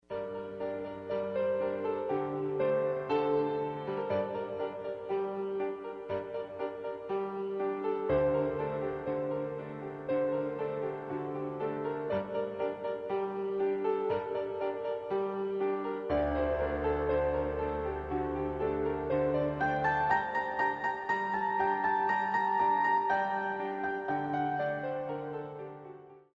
33 Piano Selections.